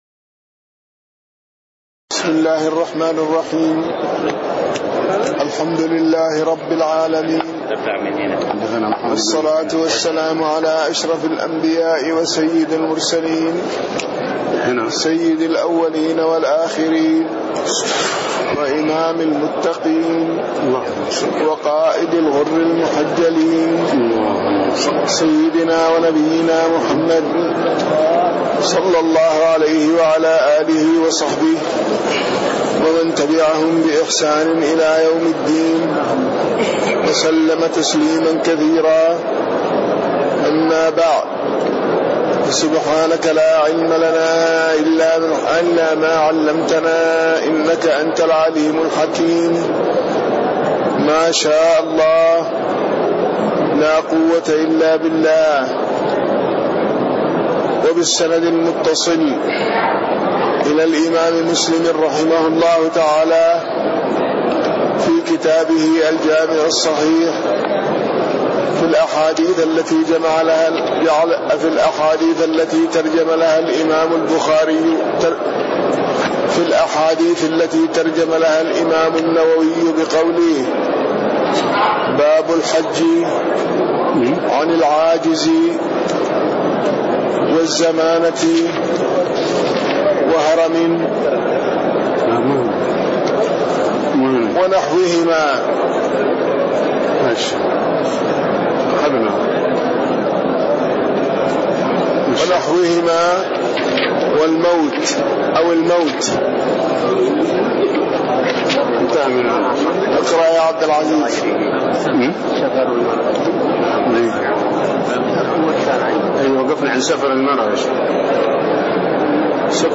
تاريخ النشر ١٤ ربيع الثاني ١٤٣٤ هـ المكان: المسجد النبوي الشيخ